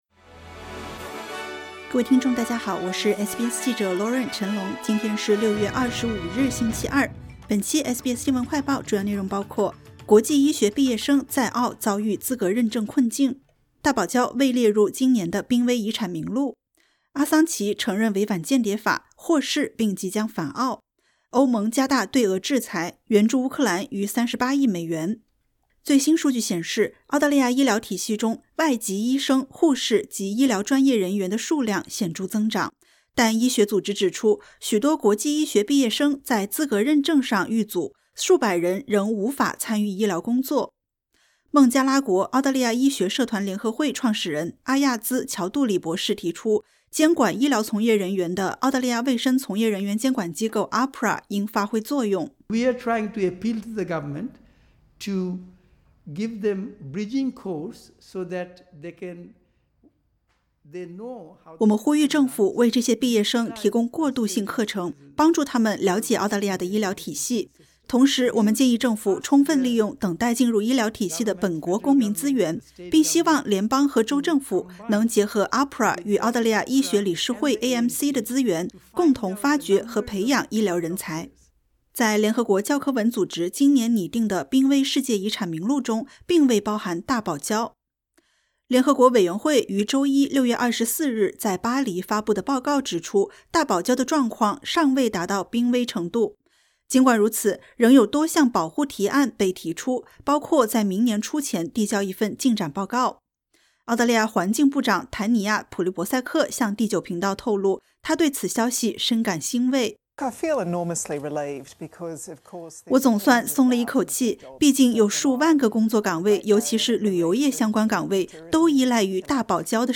【SBS新闻快报】国际医学毕业生在澳遭遇资格认证困境